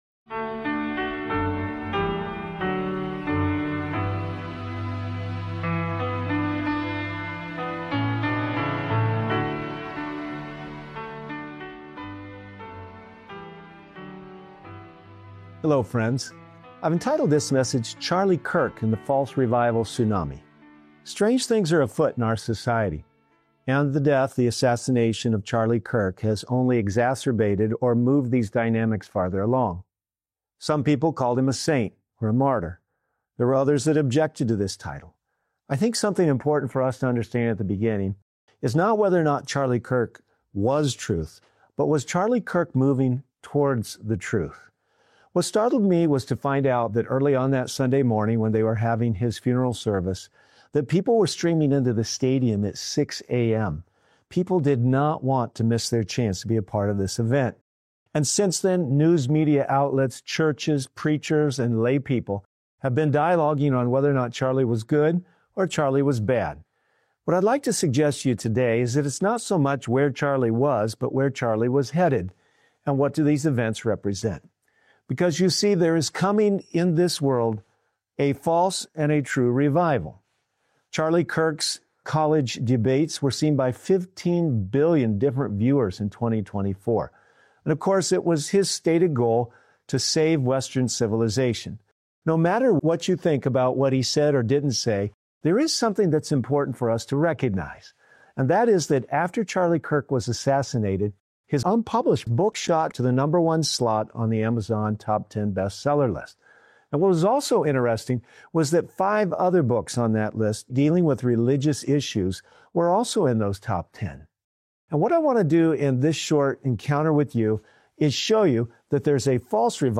This sermon exposes the difference between emotional, superficial revivals and the genuine, Spirit-led revival rooted in repentance, obedience, and sanctification. Through biblical insight and prophetic warning, it challenges believers to reject deception, uphold God’s law, and prepare for the true outpouring of the Holy Spirit that transforms lives—not just feelings.